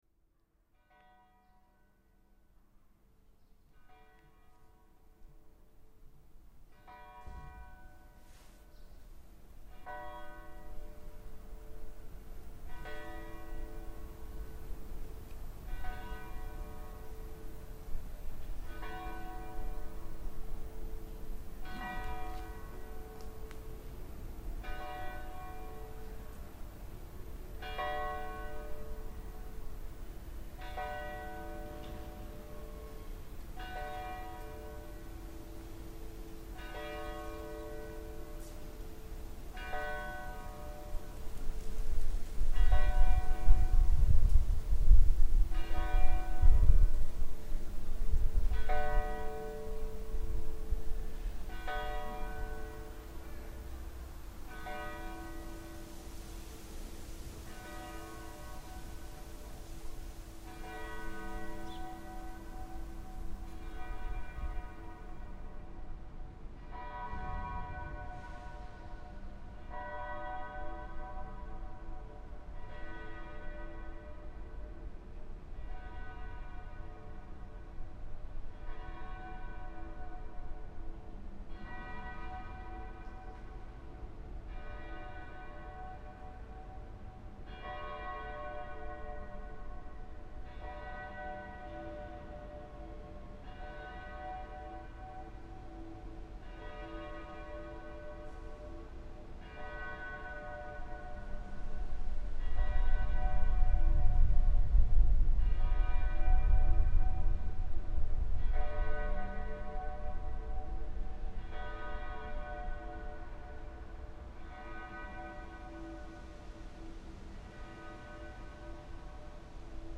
The original recording felt quite prosaic and ordinary, but I was playing around with reverbs and another of my recordings from Riga to create different effects, and thought I'd try it on the Tram recording and thought it sounded like a ghost train.
It felt a little like the tram had just rumbled out of the past and if I dared get on it, it might take me off to another time entirely.